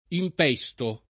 impesto [ imp $S to ]